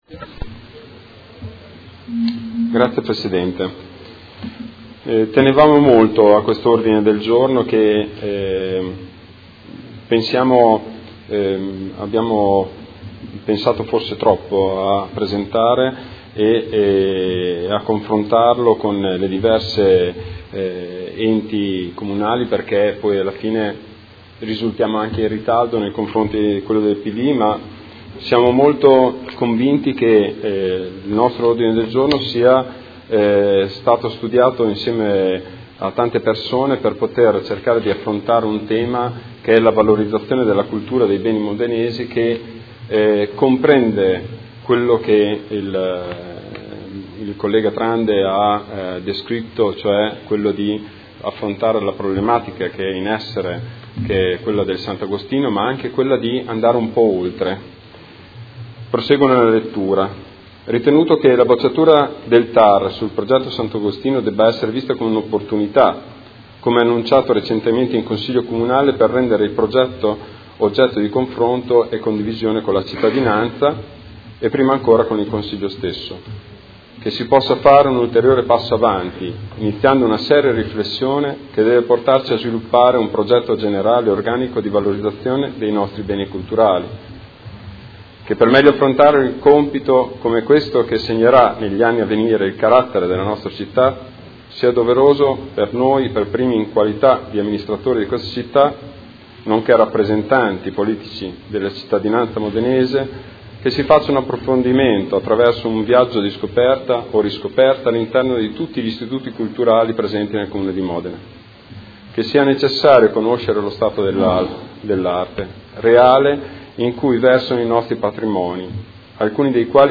Seduta del 12/05/2016. Ordine del Giorno presentato dal Gruppo Movimento Cinque Stelle avente per oggetto: Piano per la valorizzazione dei beni culturali modenesi